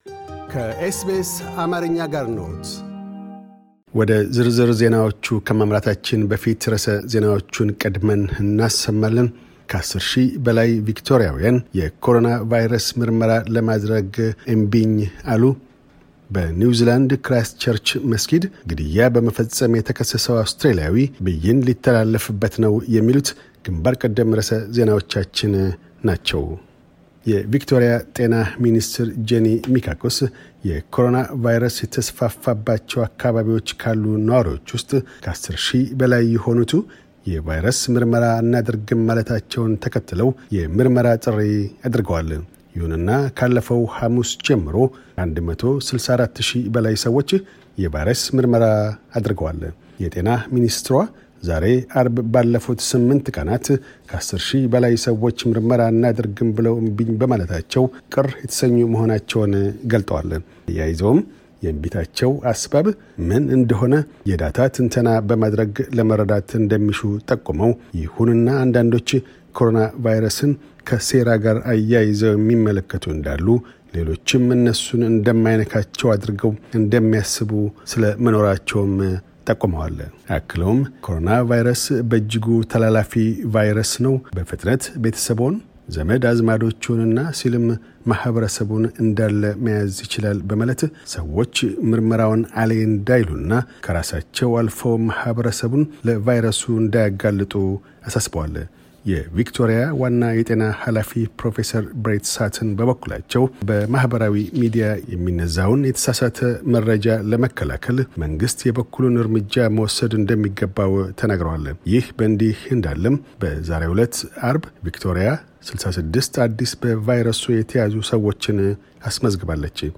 amharic_news_podact0307.mp3